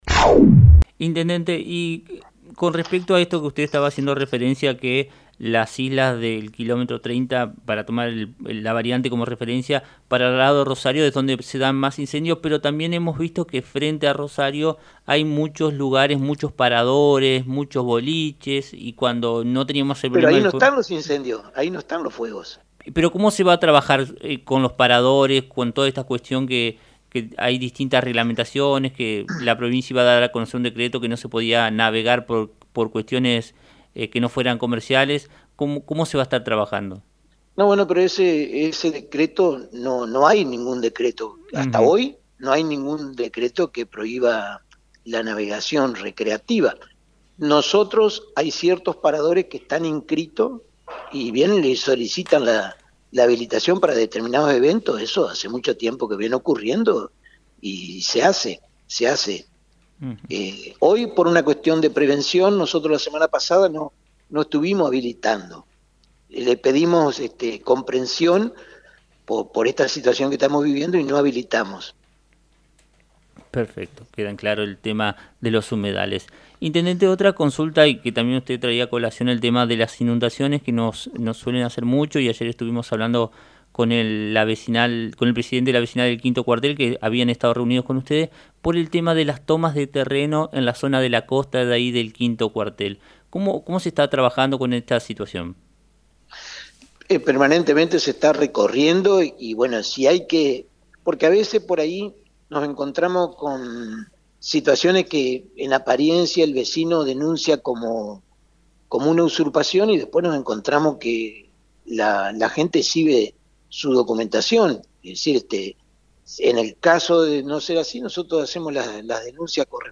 Así lo informó el Intendente Domingo Maiocco en diálogo con FM 90.3. También comentó sobre obras viales, terrenos usurpados y la propuesta presentada relacionada a los incendios en las islas del Delta.